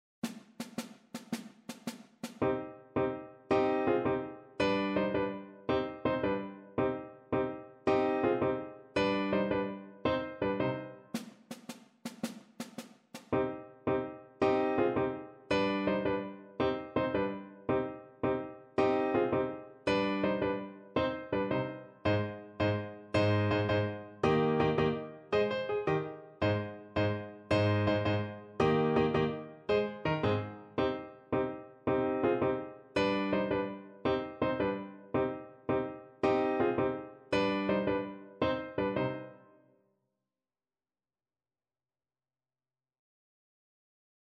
Schumann: Śmiały jeździec (na klarnet i fortepian)
Symulacja akompaniamentu